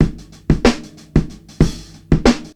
Offbeat Hop 93bpm.wav